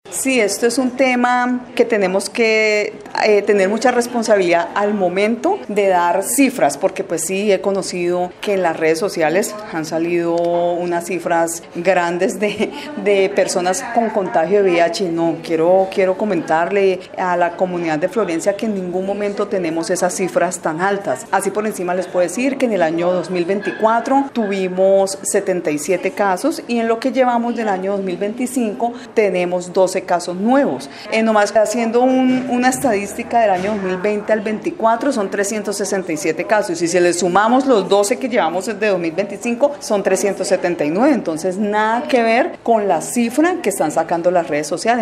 La secretaria de salud municipal, Sandra Liliana Vallejo, explicó que estos mensajes, donde se mencionan cifras irreales de pacientes con dicha enfermedad, solo desinforman y generan zozobra entre la comunidad.